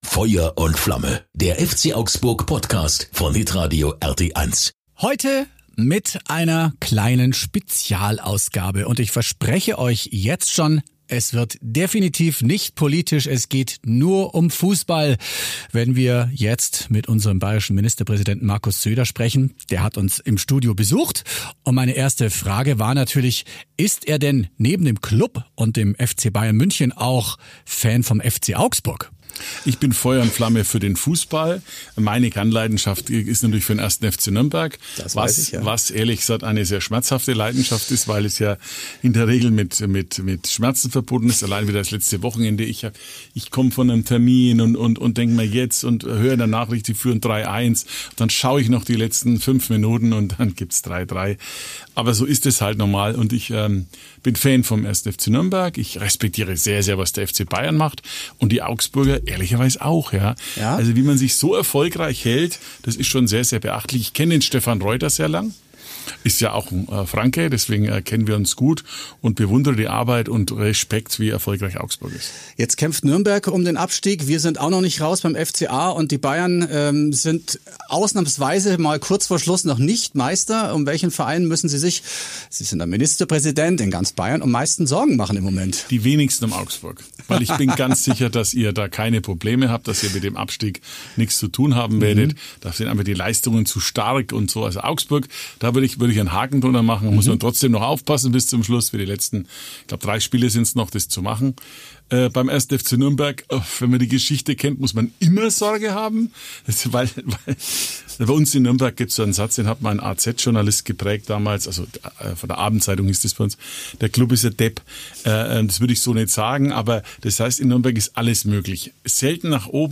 Zu Gast: Ministerpräsident Markus Söder